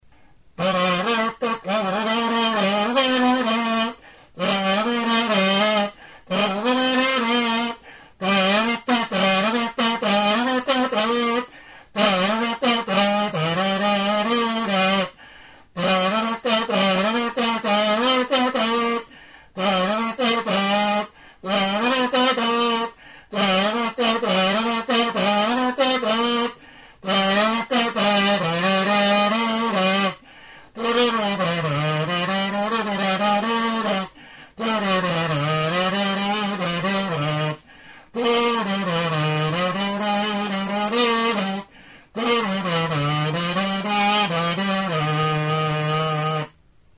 Membranófonos -> Mirliton
Grabado con este instrumento.
Intsusa makila zati bati barrukoa hustu eta tutuarekin egindako 'mirliton' gisako turuta da.